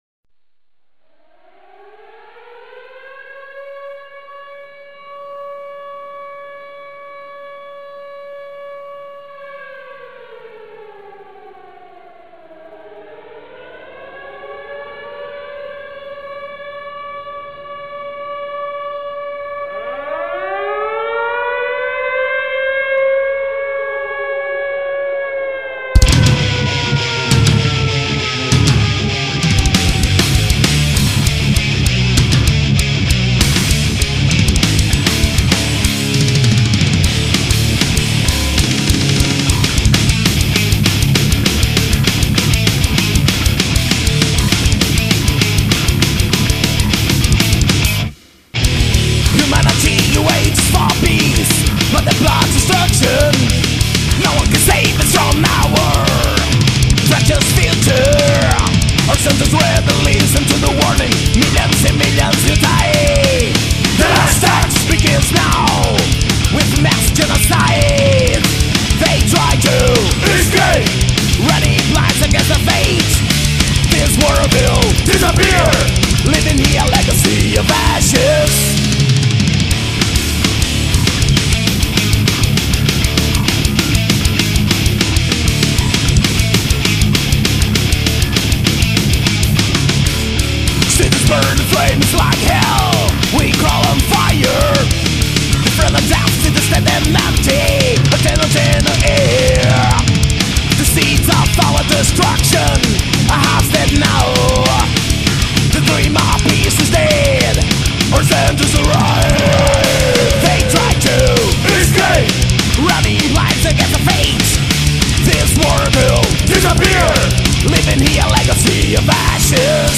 Brazilian Thrash Metal